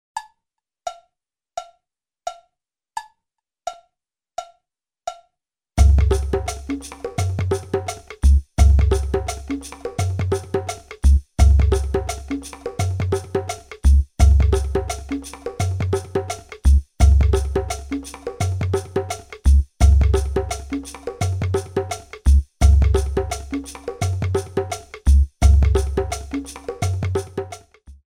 15 tracks of original percussion music
Nice consistent long tracks  - world music style